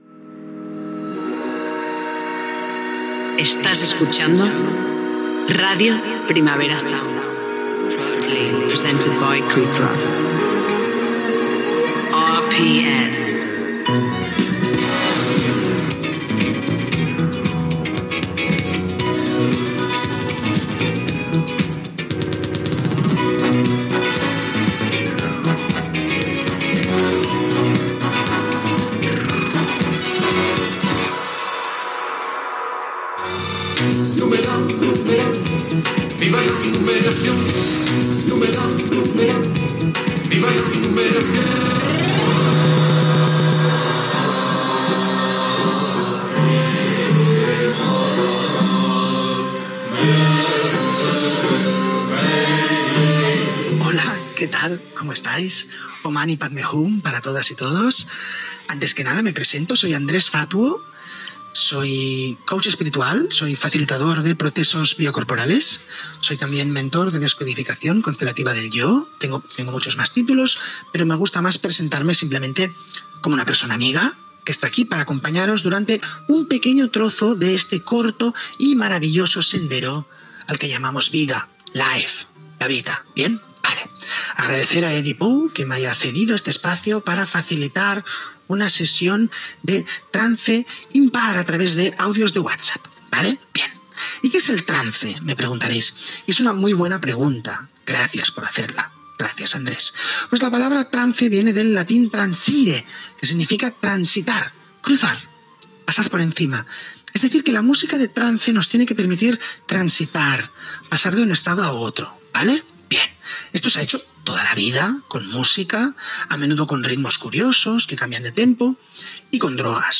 Identificació de l'emissora i publicitat, sintonia, presentació del programa dedicat al "trance impar", la relació de la música vinculada als estats de trànsit
Entreteniment